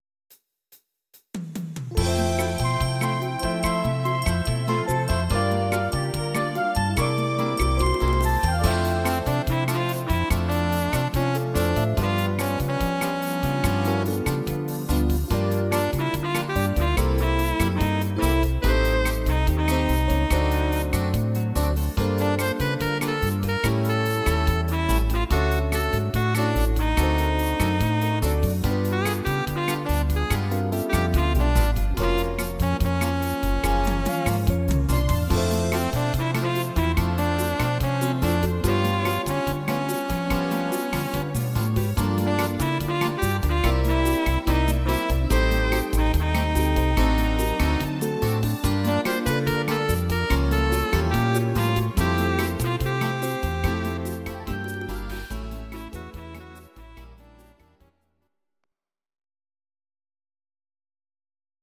Please note: no vocals and no karaoke included.
instr. sax